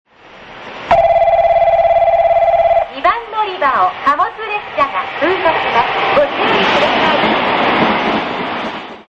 通過放送（貨物列車）   九州主要 CMT